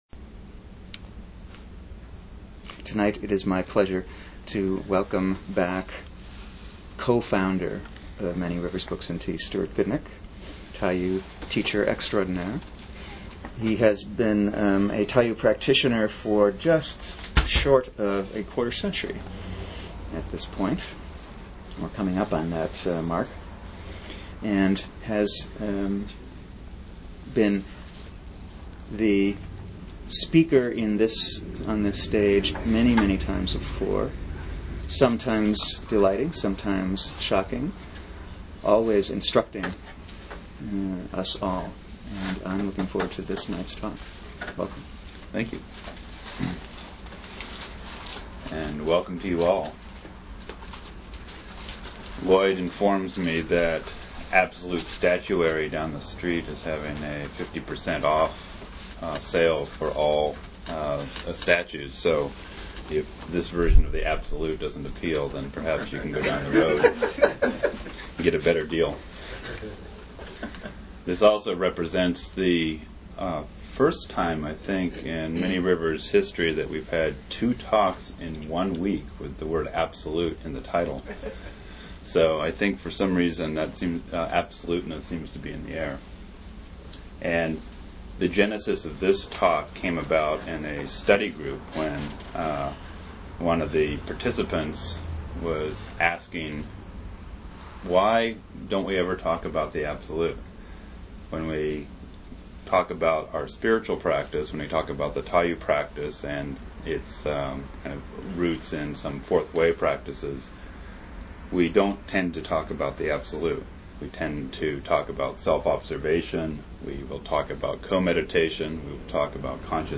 Archive of an event at Sonoma County's largest spiritual bookstore and premium loose leaf tea shop.
Join us for a lively discussion on this most profound of spiritual topics, where we will seek to establish refuge from the spiritual glibness that passes these days for Wisdom.